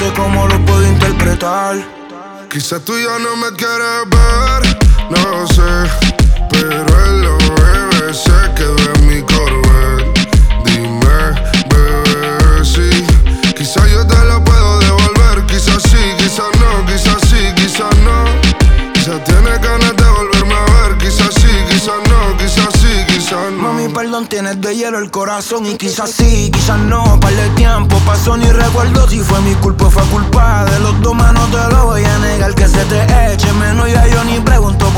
Жанр: Латиноамериканская музыка / Русские
# Urbano latino